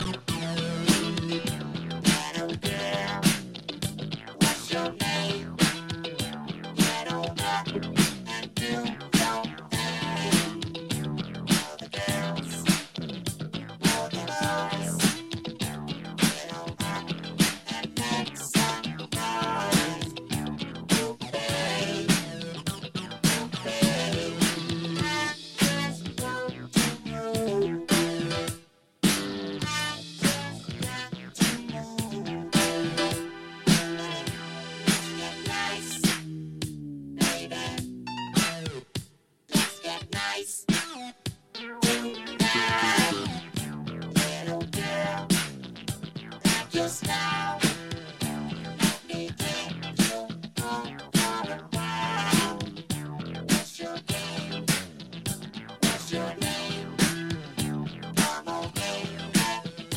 7-inch Vocal Version